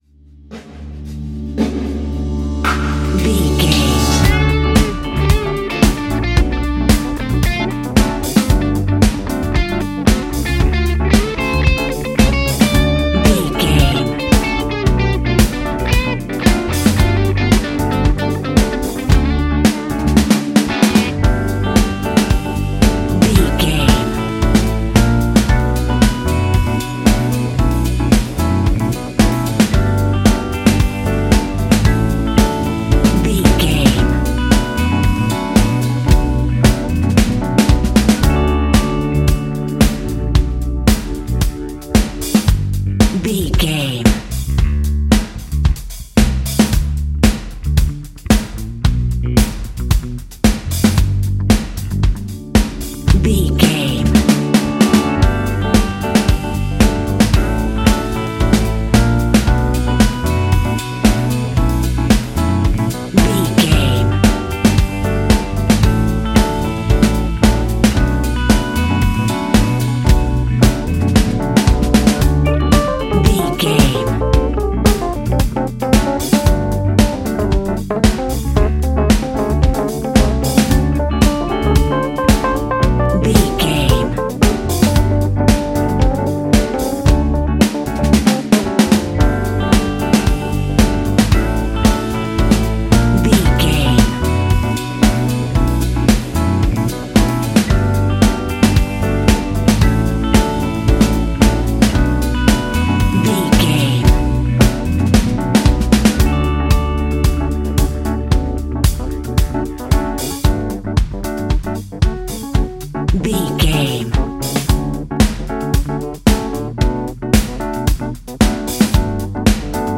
Ionian/Major
D♭
house
electro dance
synths
techno
trance
instrumentals